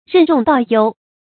任重道悠 注音： ㄖㄣˋ ㄓㄨㄙˋ ㄉㄠˋ ㄧㄡ 讀音讀法： 意思解釋： 見「任重道遠」。